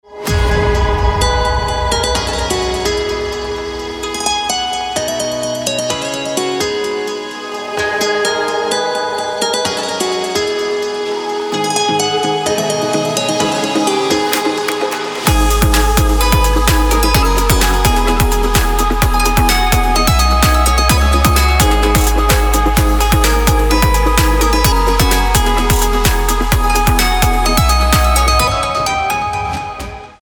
• Качество: 320, Stereo
dance
без слов
club
восточные